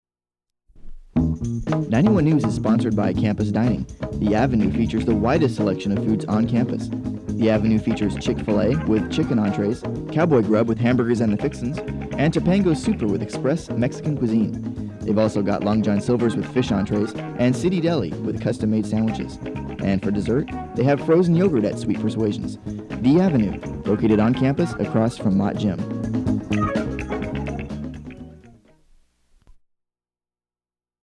Advertisement for The Avenue, a group of dining options at Cal Poly